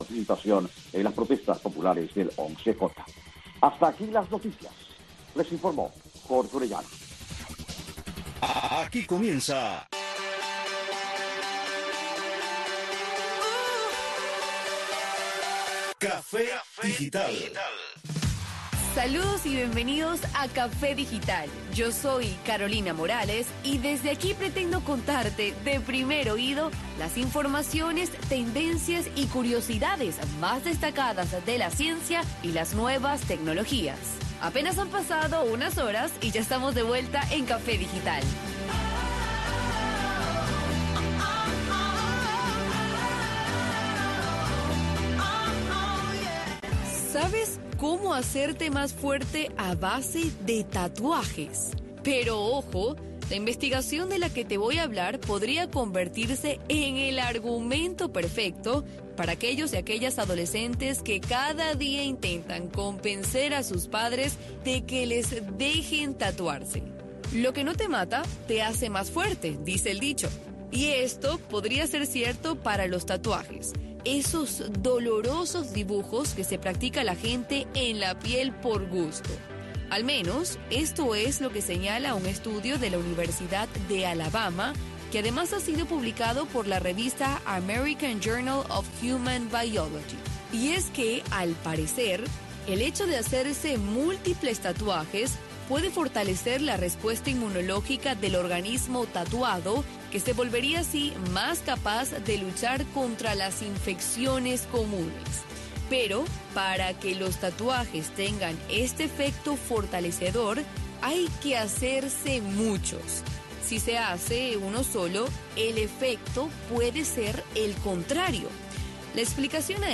Café digital es un espacio radial que pretende ir más allá del solo objetivo de informar sobre nuevos avances de la ciencia y la tecnología.
Café digital traerá invitados que formen parte de la avanzada científica y tecnológica en el mundo y promoverá iniciativas e ideas que puedan llevar a cabo los jóvenes dentro de Cuba para dar solución a sus necesidades más cotidianas.